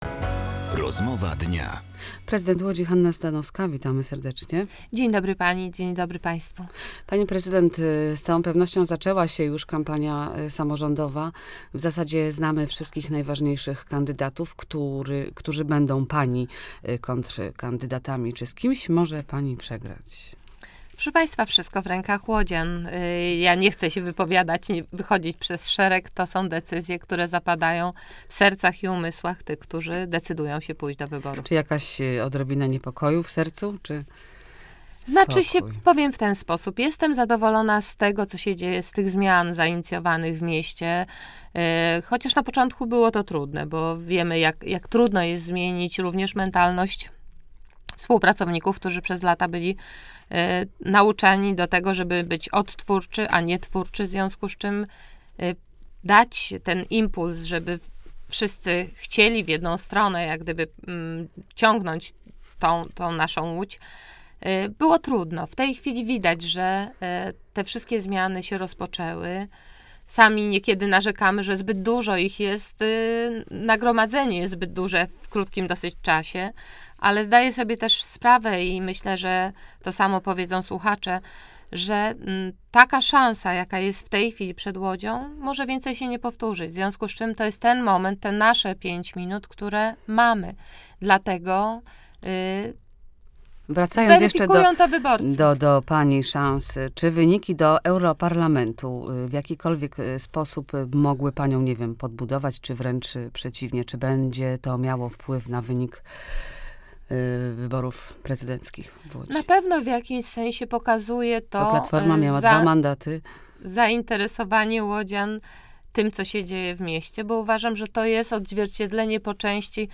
W Łodzi powstanie wiele nowych miejsc parkingowych, a ich budowę zapowiedziała w Radiu Łódź prezydent Hanna Zdanowska.